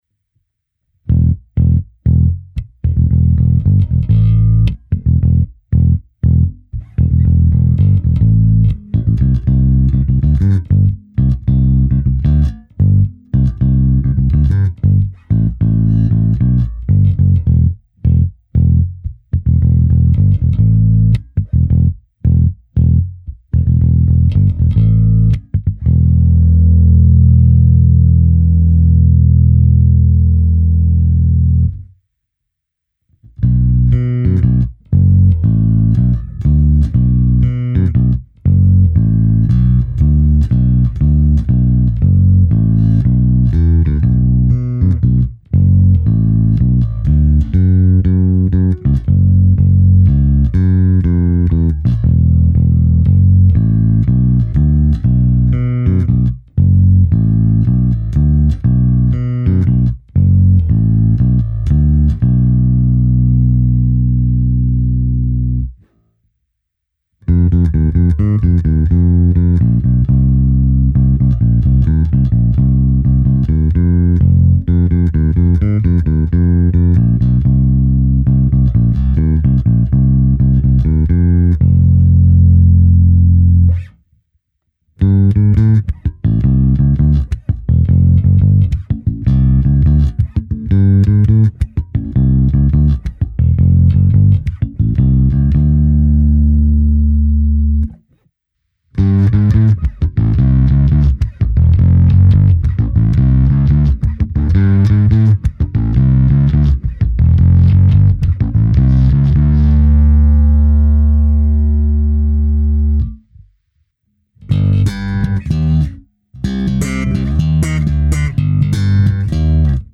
Zvuk je trochu nezařaditelný. Dobrý, pevný, ale charakter Music Manu v něm není ani v náznaku, což jsem upřímně řečeno vlastně vůbec neočekával. Originál Music Man snímač a aktivní elektronika jsou prostě téměř nenahraditelné. Tato aktivní elektronika poskytuje hodně čistý, ničím nezabarvený zvuk, a nutno dodat, že má hodně silný výstup, velký zdvih.
Nahrávka se simulací aparátu, kde bylo použito i zkreslení a hra slapem